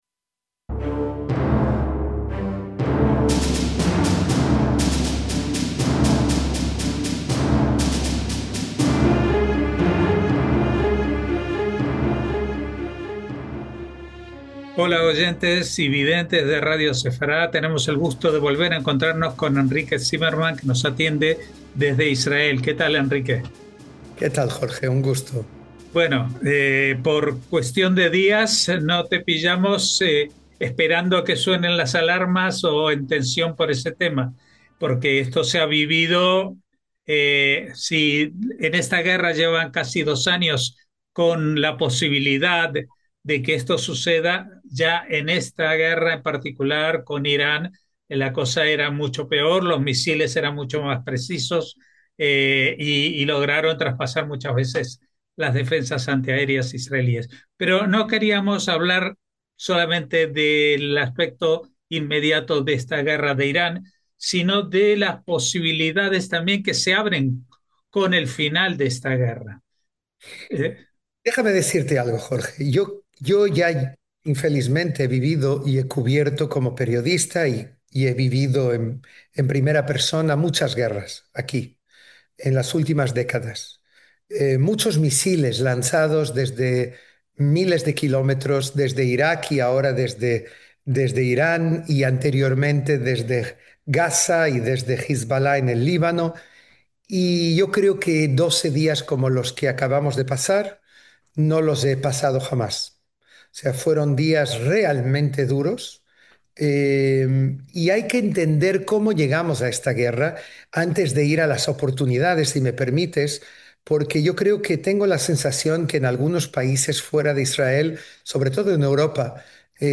A FONDO - Hablamos con el periodista (y bastante más) Henrique Cymerman sobre la reciente guerra con Irán.